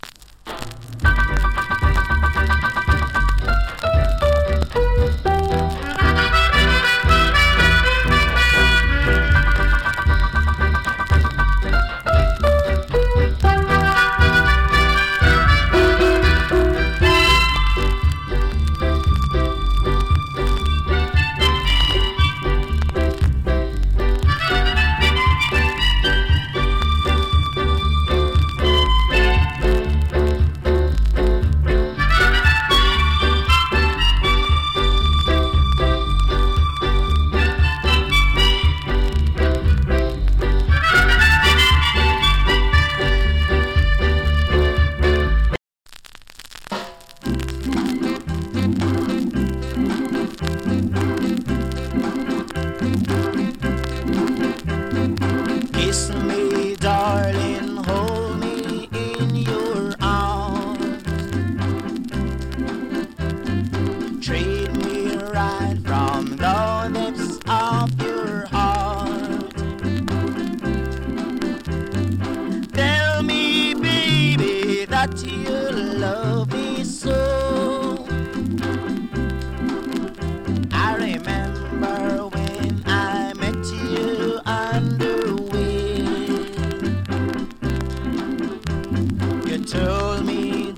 チリ、パチノイズ多数有り。
HARMONICA CUT ! & NICE VOCAL CARIB MOOD TUNE !!